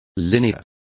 Complete with pronunciation of the translation of linear.